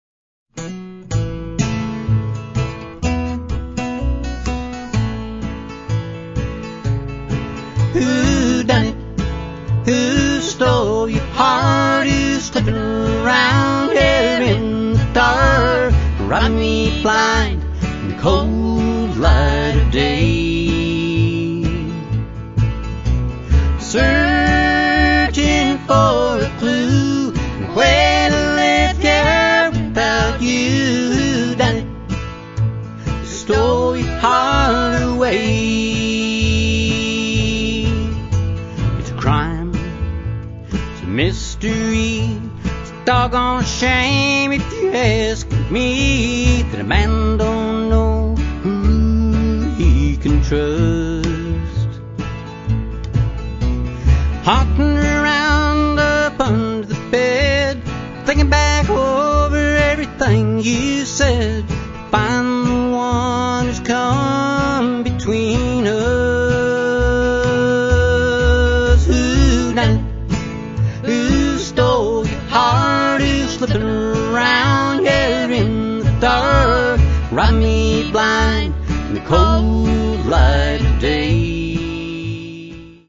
guitar
a definite old-time country feel to it